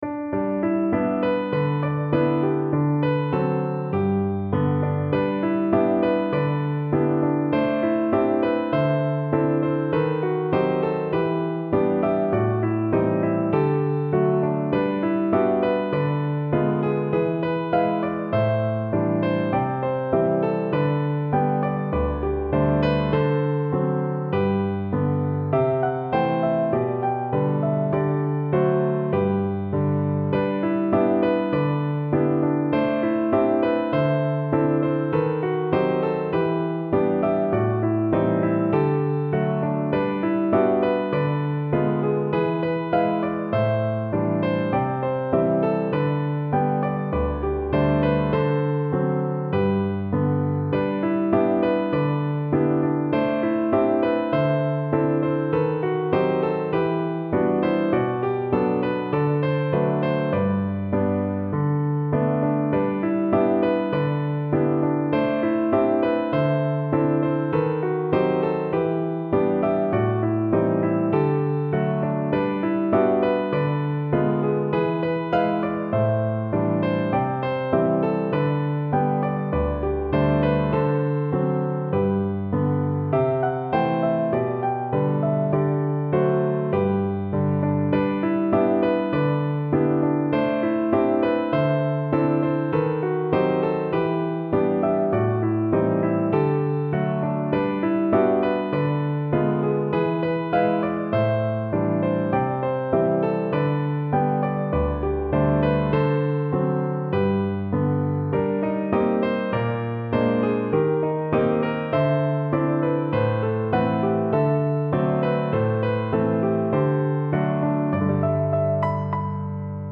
Audio acc. piano instrumental.